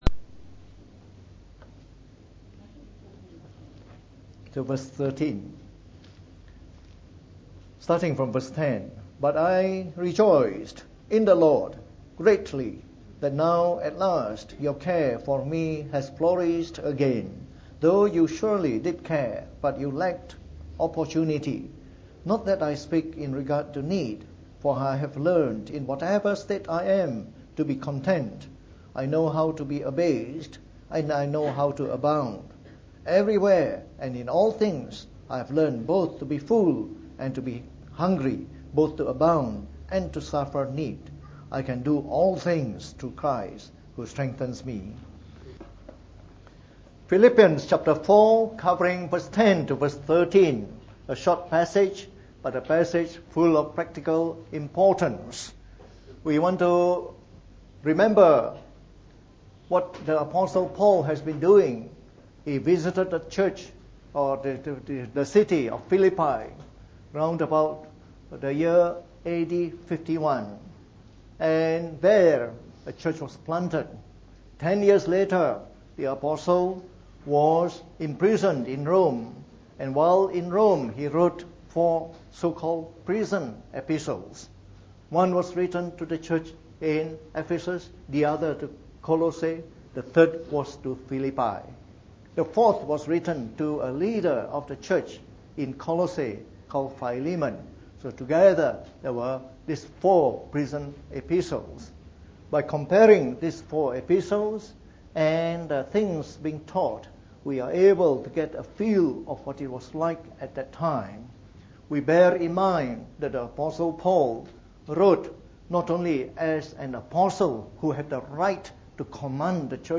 From our series on the Epistle to the Philippians delivered in the Morning Service.